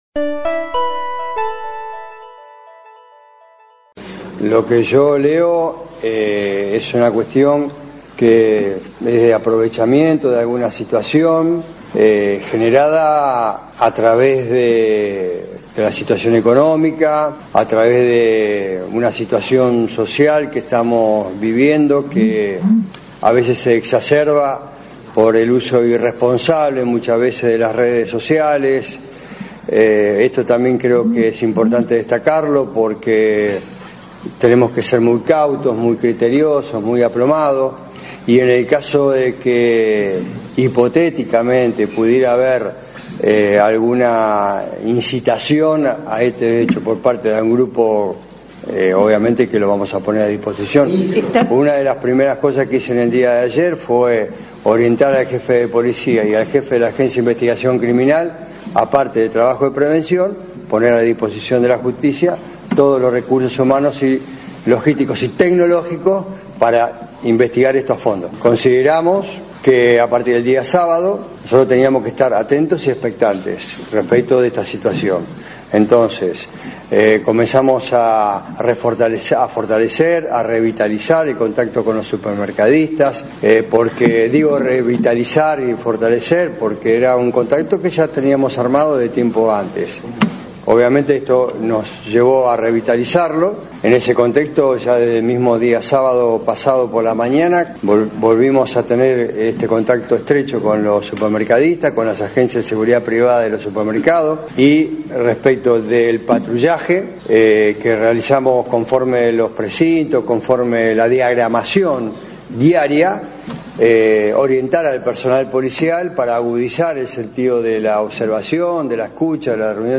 El Ministro de Seguridad de Santa Fe brindó una conferencia de prensa en la que se refirió a los robos y los intentos de robo registrados en la ciudad de Santa Fe y en Rosario.